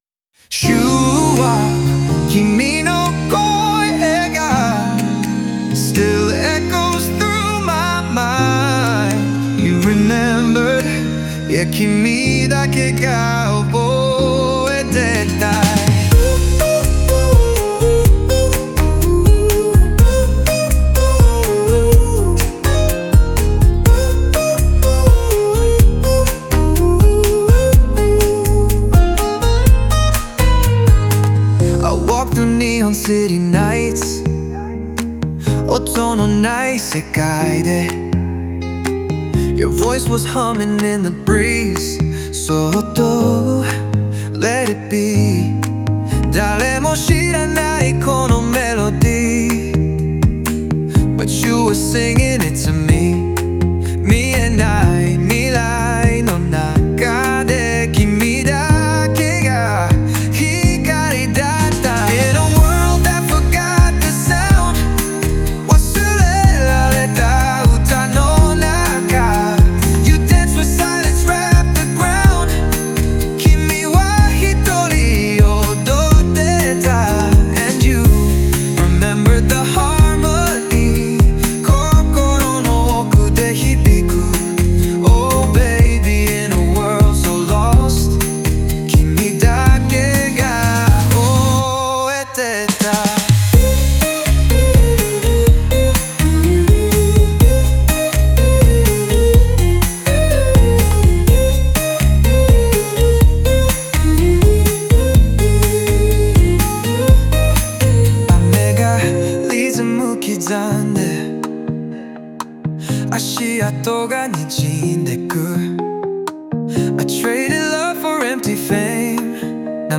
オリジナル曲♪
この歌詞は、「記憶」と「愛」を軸に描かれたラブソウルバラードです。
過去の栄光に溺れそうになった主人公が、最も純粋な愛に立ち返るまでの心の旅路を、ソウルフルなリズムと共に奏でています。